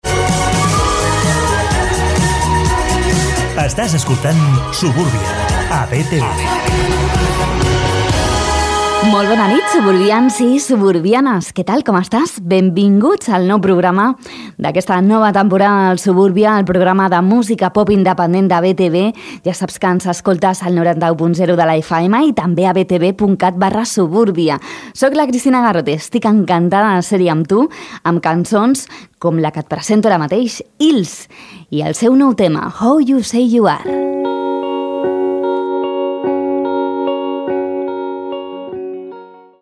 Careta, presentació i tema musical
Musical